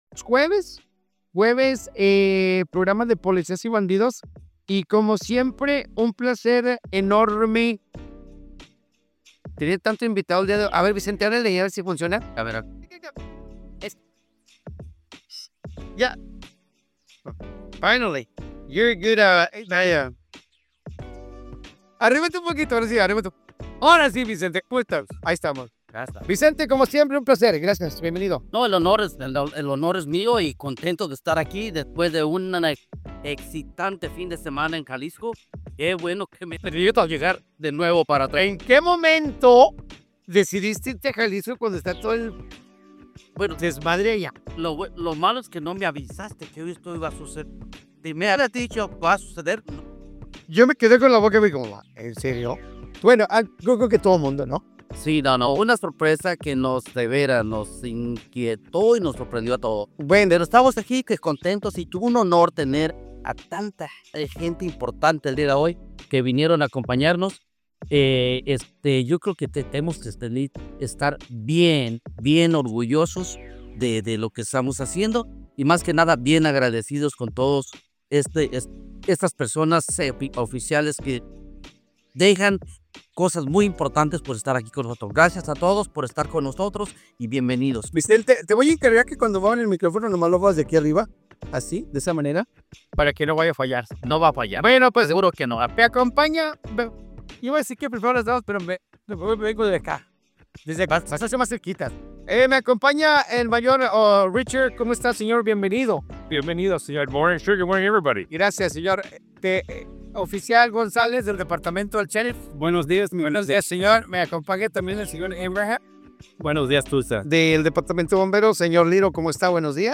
En la más reciente emisión del programa comunitario, representantes del Departamento de Bomberos, la Policía de Tulsa (TPD), la Oficina del Sheriff y la Fiscalía abordaron temas críticos de seguridad, prevención y salud mental que impactan directamente a la comunidad del área de Tulsa.